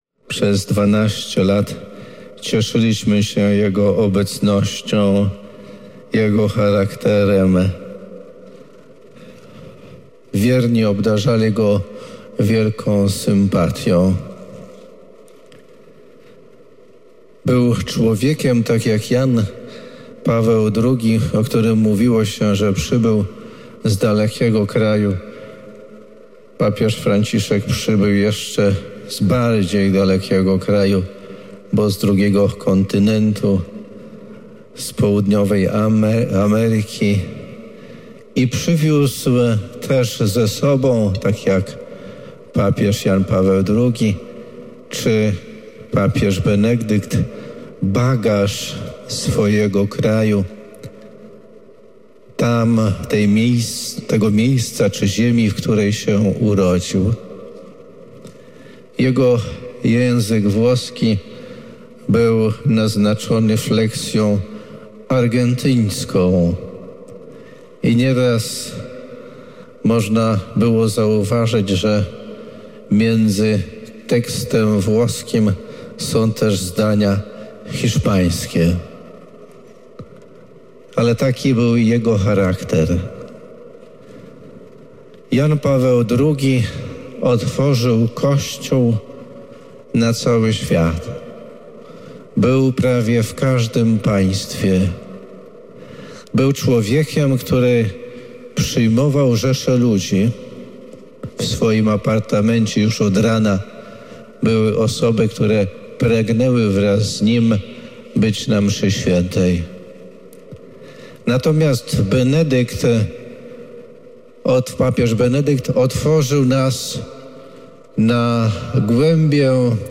W Sanktuarium Miłosierdzia Bożego w Łomży pod przewodnictwem biskupa Janusza Stepnowskiego została odprawiona uroczysta msza św. w intencji zmarłego papieża.
Zapraszamy do wysłuchania homilii, w której bp Stepnowski przybliżył ponad 12-letni pontyfikat Franciszka.